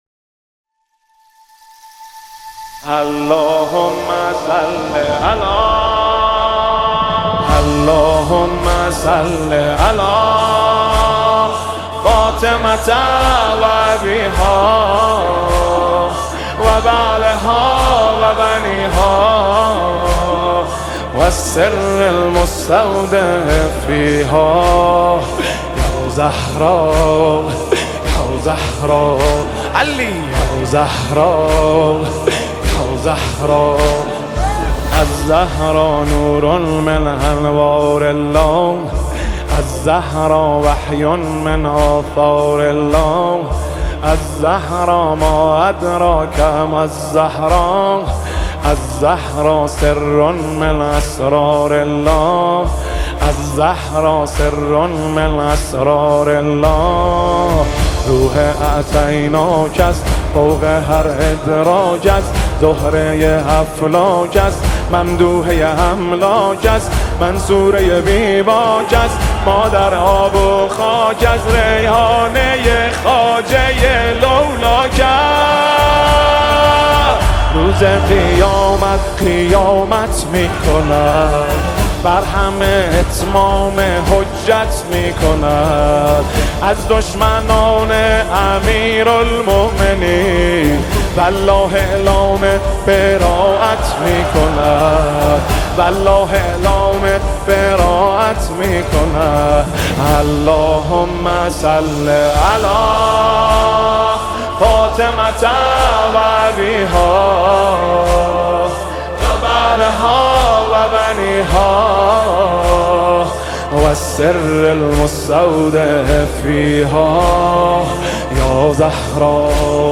با تنظیم استودیویی